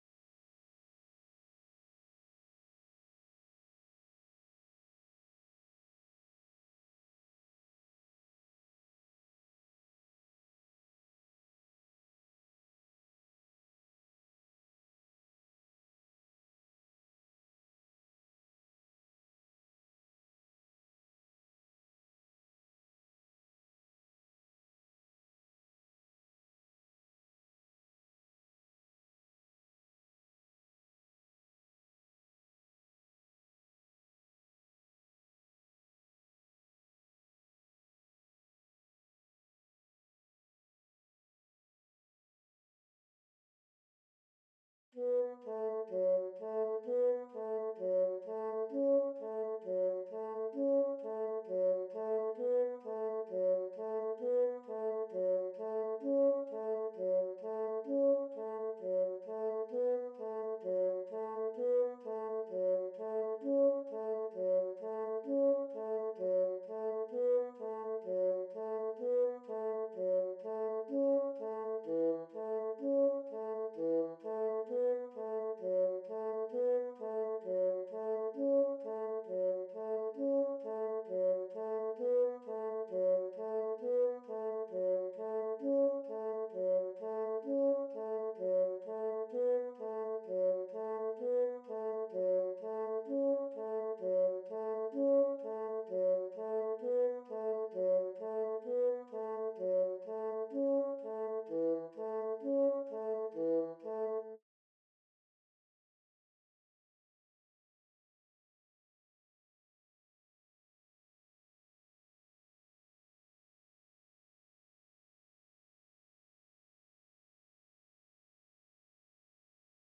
14. Bassoon 2 (Bassoon/Normal)
RecantationGrounds-32-Bassoon_2.mp3